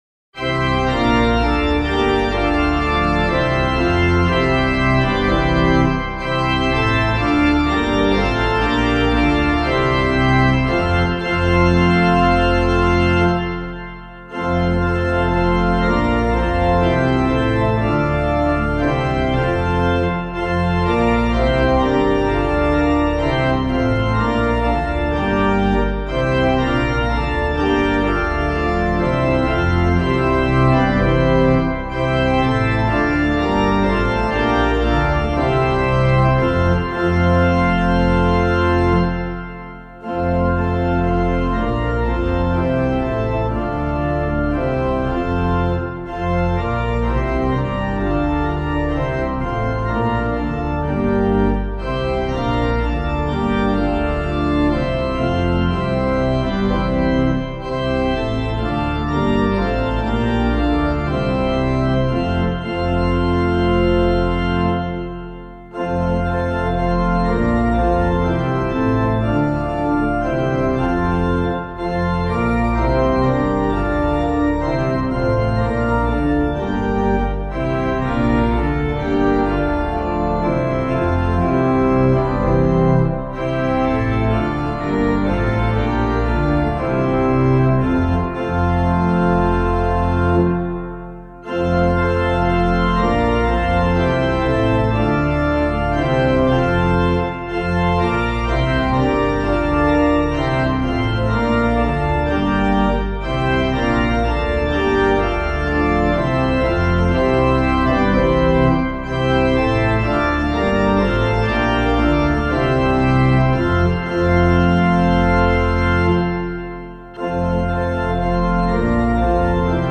Composer:    Rouen church melody, 16th or 17th cent., included in Paris Antiphoner, 1681.